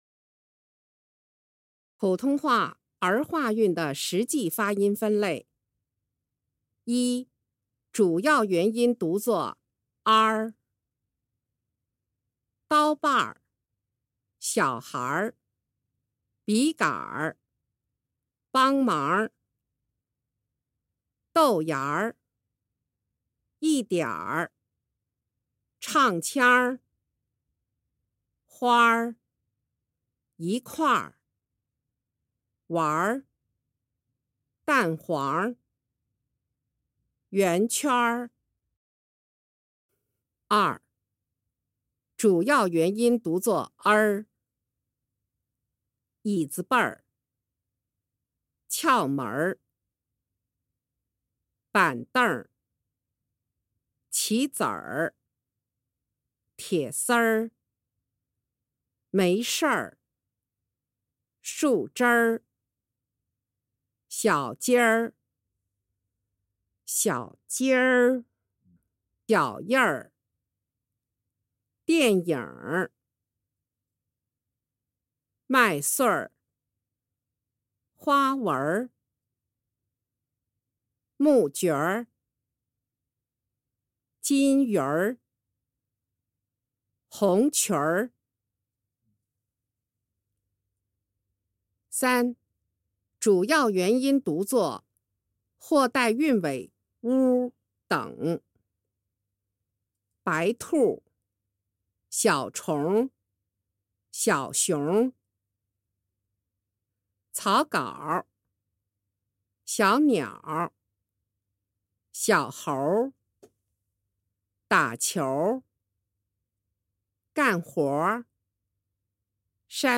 2.第十四讲儿化发音例词-女声